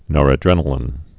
(nôrə-drĕnə-lĭn)